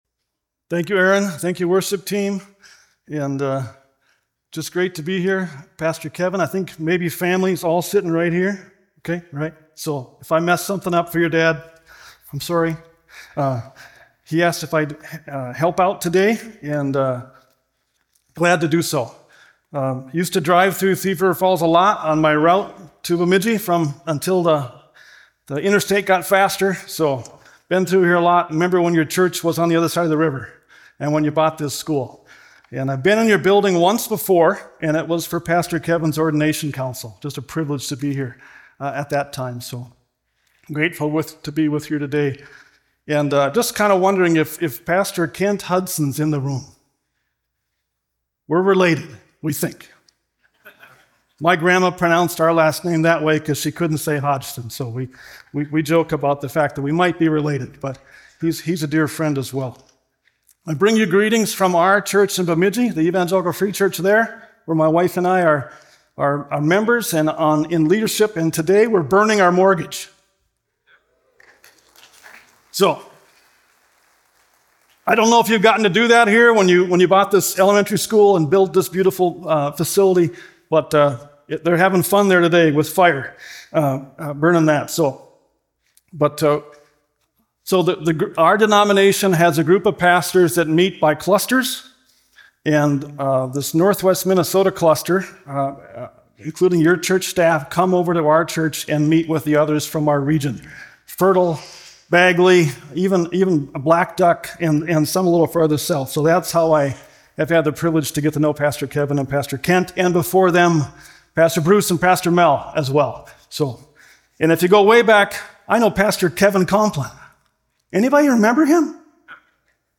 A message from the series "1 Peter." Christ-centered hope leads to holy living. Christians should set their hope fully on the grace of Jesus Christ, and that this hope should lead to holy living, which includes imitating God and having a holy fear of Him.